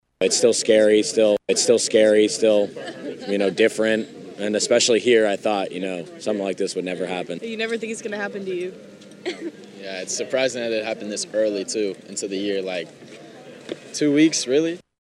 University of Arkansas students share their reactions to Monday’s false report of an active shooter on campus.